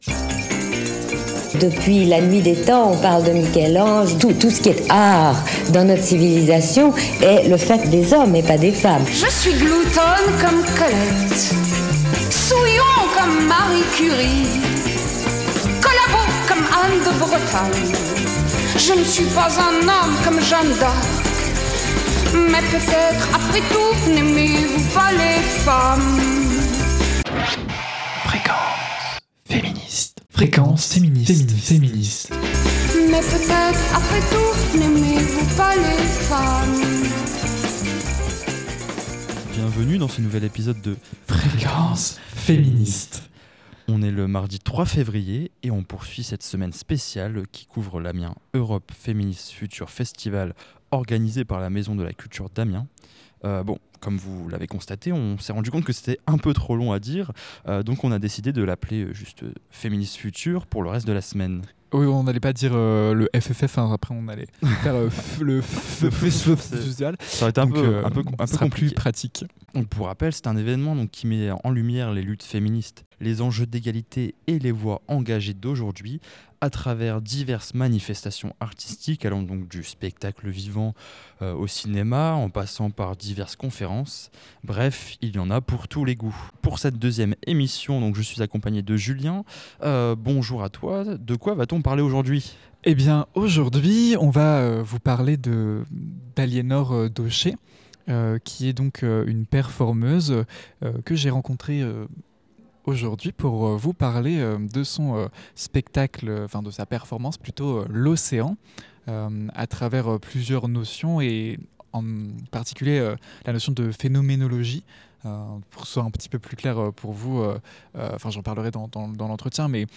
Au programme donc : interviews, chroniques, suggestions littéraires … vous attendent pour profiter avec nous de ces moments de partage et de rencontre.